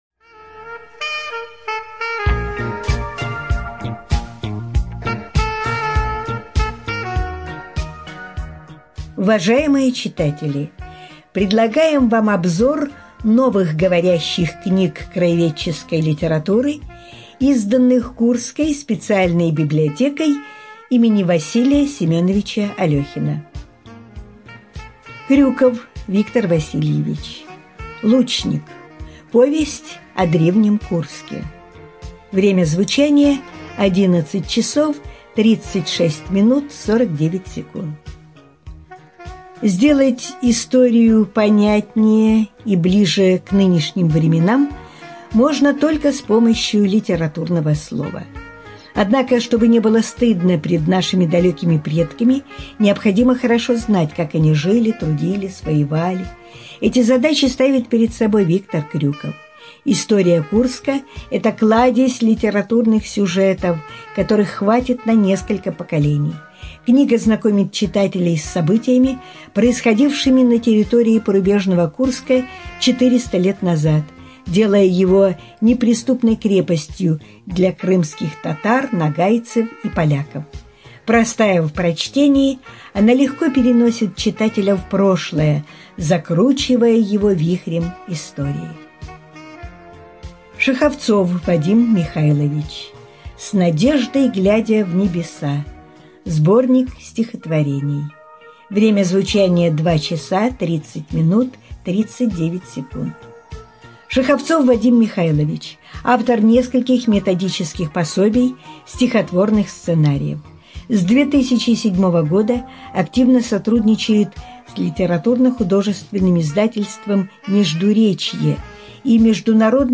Студия звукозаписиКурская областная библиотека для слепых